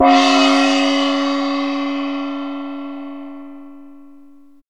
Index of /90_sSampleCDs/Roland LCDP03 Orchestral Perc/CYM_Gongs/CYM_Gongs Dry
CYM BENDGONG.wav